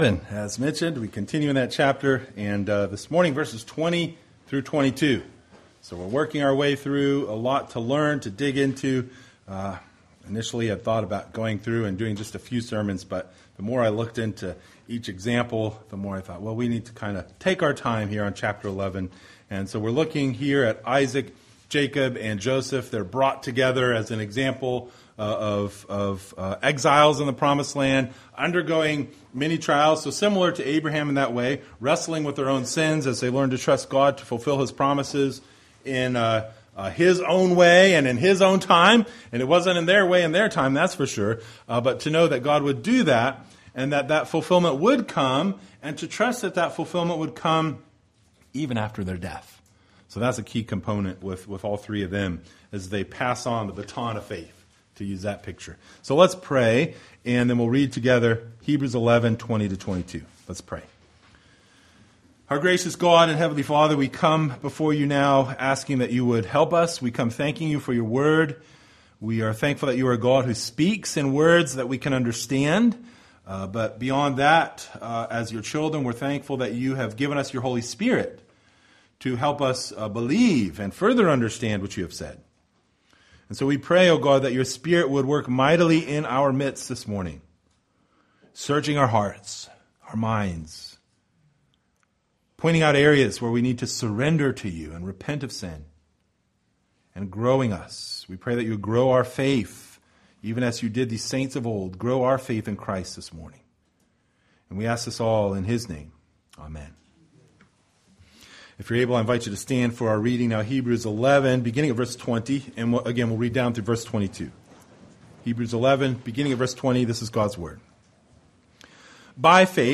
Hebrews 11:20-22 Service Type: Sunday Morning Related « Of the Law of God